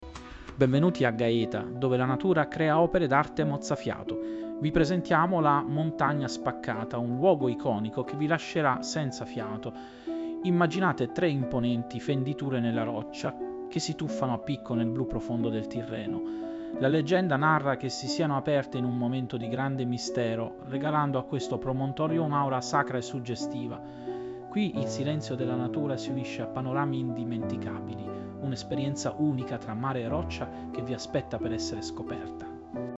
SCOPRI GAETA PODCAST Riassunto disponibile Ascolta i punti chiave in 60 secondi.
Riassunto disponibile Premi play per ascoltare 0:00 --:-- AI Speaker Virtuale Note Legali Entra nella community di Scopri Gaeta!